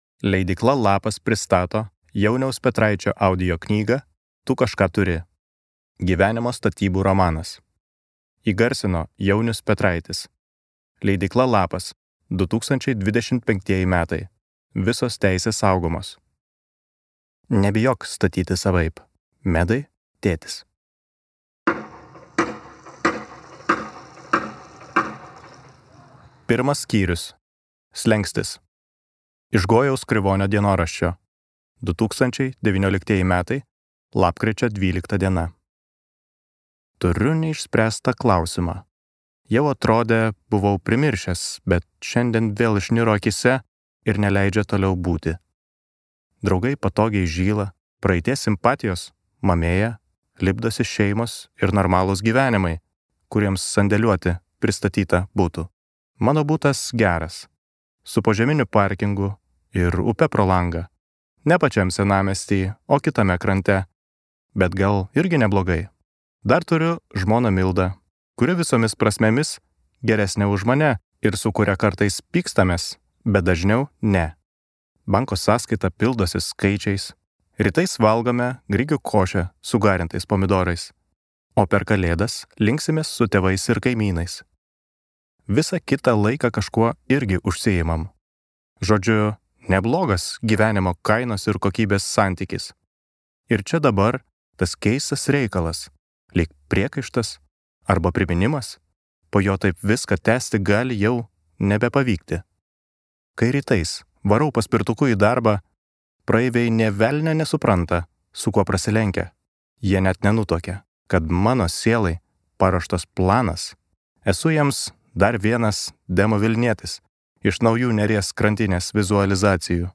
Gyvenimo statybų romanas | Audioknygos | baltos lankos